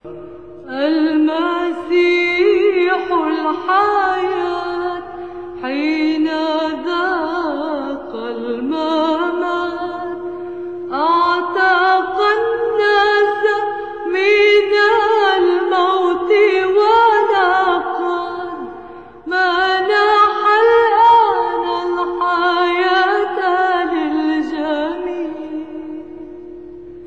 Throughout the years Fairouz has shared her faith with her fans in her performances on the Holy Fridays making the heaven closer to the earth , and giving the words a simpler path with the musical component of her angelical voice , making of this tradition a date awaited by her fans , among which the few lucky ones will have the chance to meet with her on one of these Holy Fridays ,  in these pages I will try to share with you some traditional prayers that Fairouz has been performing throughout the past years .
This One is from the Holy Friday Tradition